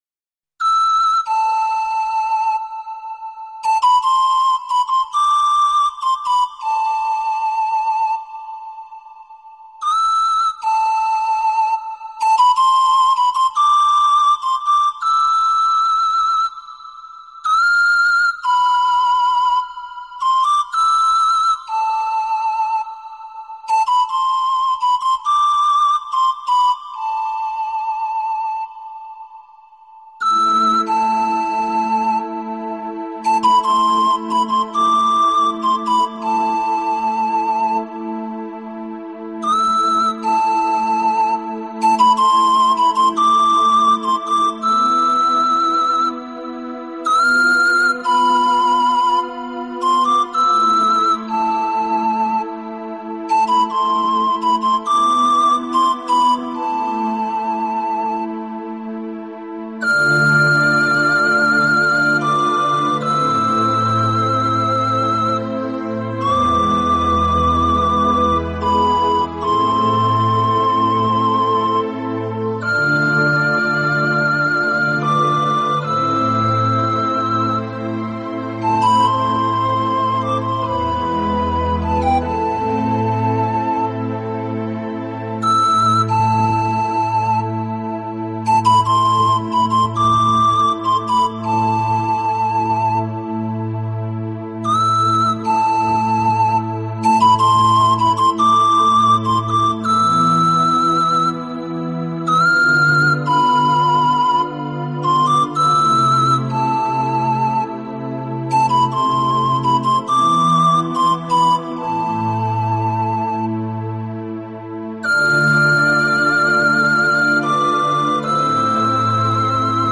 器配置，使每首曲子都呈现出清新的自然气息。